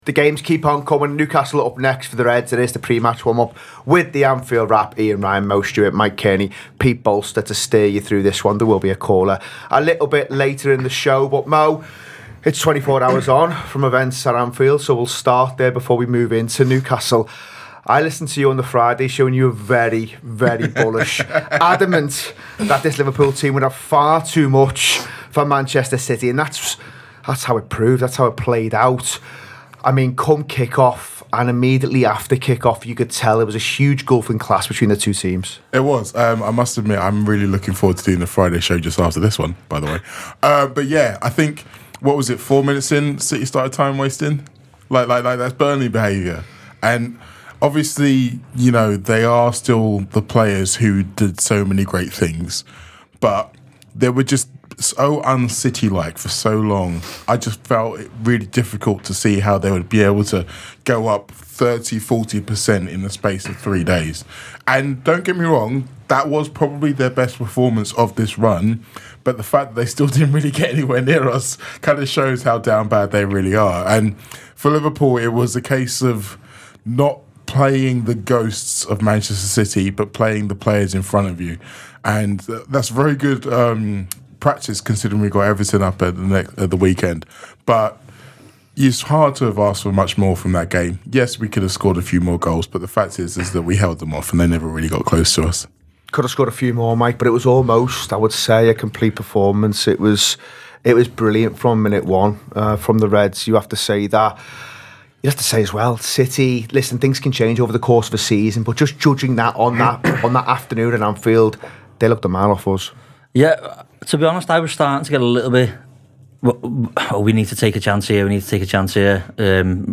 Below is a clip from the show – subscribe for more pre-match build up around Newcastle United v Liverpool…